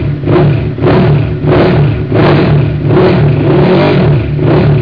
Vous allez trouver tous les sons de bécanes ici, ça va du bruit d'échappement quelconque aux moteurs de sportives en furie, je vous laisse découvrir...
voxan.wav